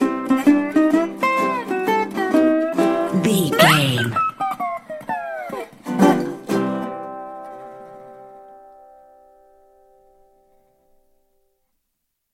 Ionian/Major
acoustic guitar
percussion